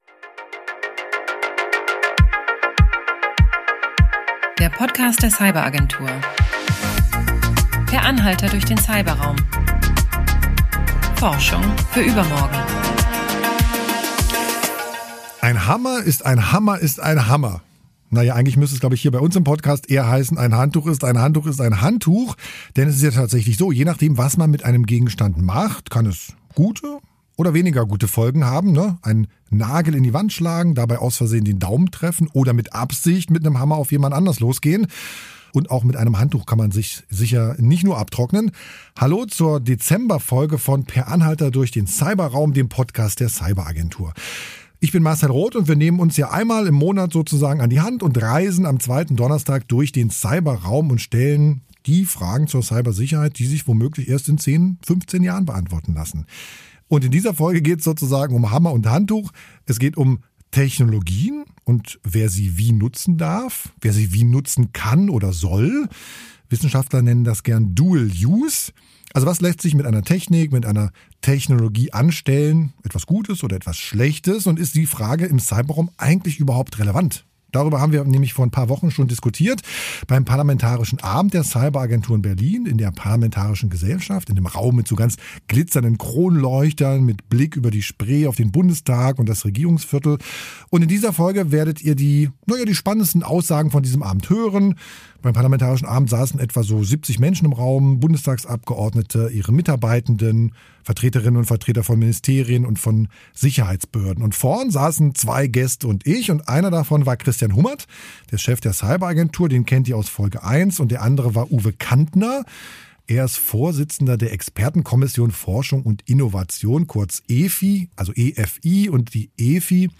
Beim parlamentarischen Abend der Cyberagentur